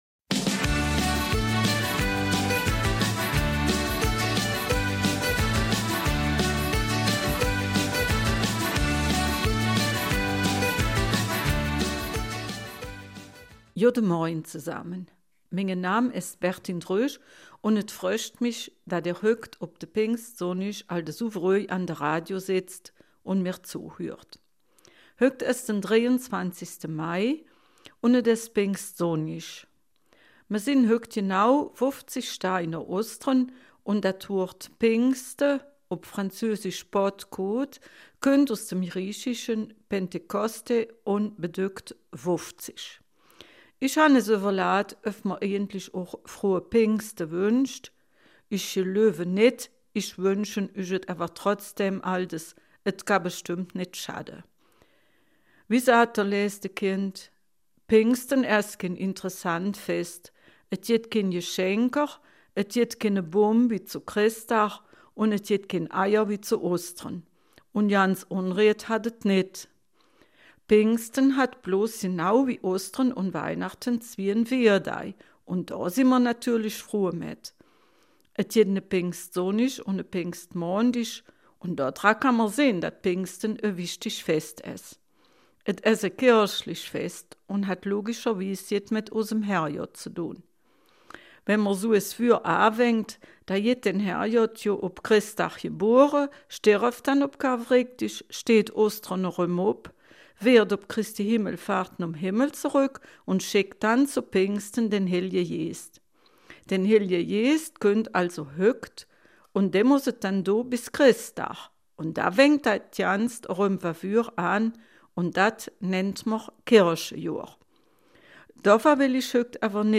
Eifeler Mundart - 23. Mai